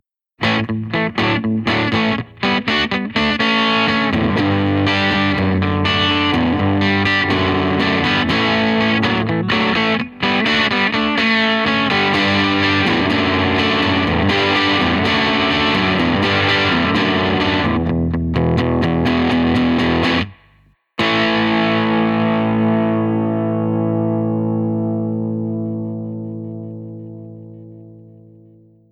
Ici mise en valeur avec la belle saturation du Delta King de chez Supro .
Micro aigu